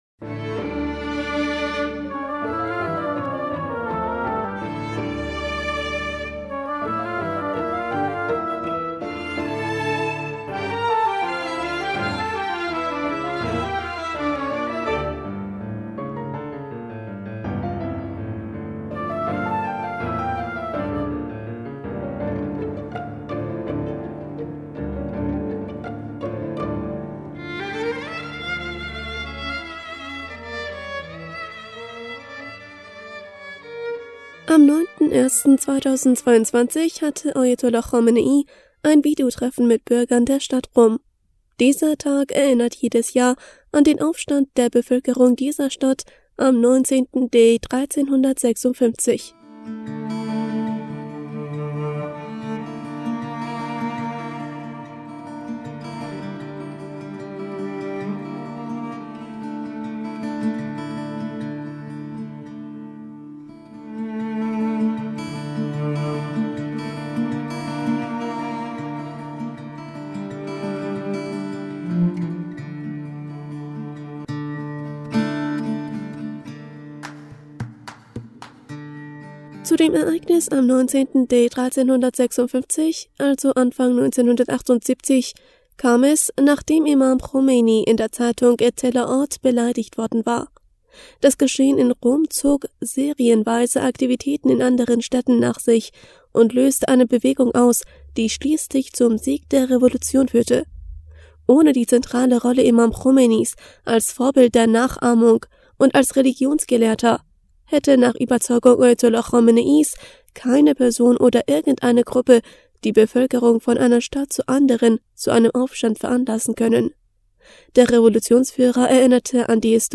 Am 9. 1. 2022, hatte Ayatollah Khamenei ein Videotreffen mit Bürgern der Stadt Qom.